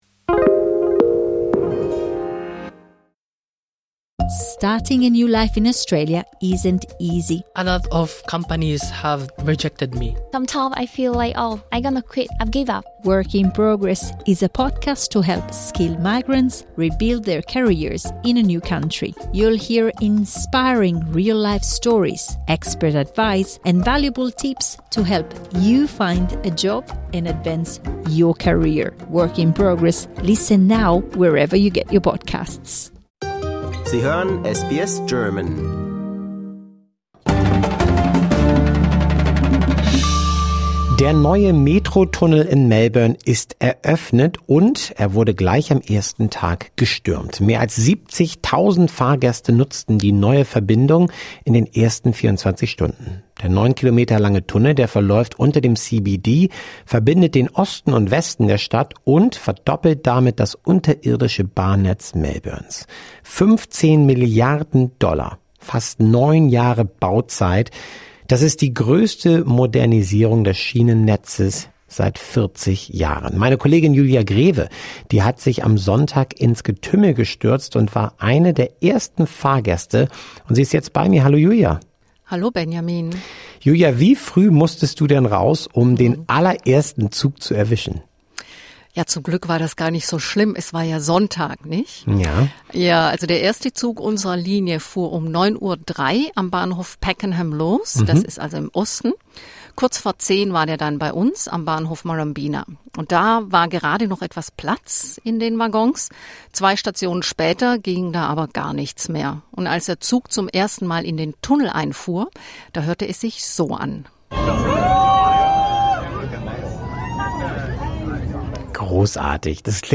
Der neue Metro-Tunnel in Melbourne ist eröffnet – und er wurde gleich am ersten Tag gefeiert: Mehr als 70.000 Fahrgäste nutzten die neue Verbindung in den ersten 24 Stunden. Der neun Kilometer lange Tunnel verläuft unter dem CBD, verbindet den Osten und Westen der Stadt und verdoppelt damit das unterirdische Bahnnetz Melbournes. 15 Milliarden Dollar, fast neun Jahre Bauzeit – das ist die größte Modernisierung des Schienennetzes seit 40 Jahren. Wir haben uns am Sonntag ins Getümmel gestürzt und waren bei den ersten Fahrgästen dabei.